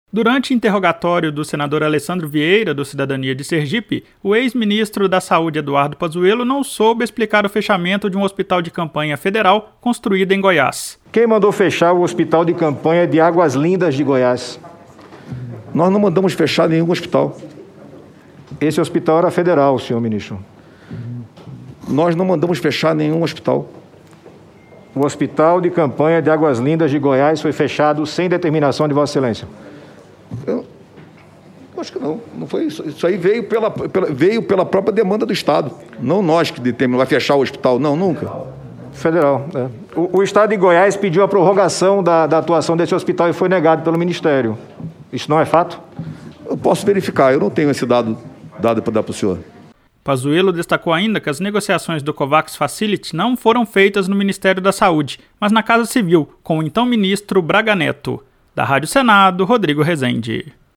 Durante o interrogatório do senador Alessandro Vieira (Cidadania-SE), o ex-ministro da Saúde Eduardo Pazuello não soube explicar o fechamento de um hospital de campanha federal construído em Goiás.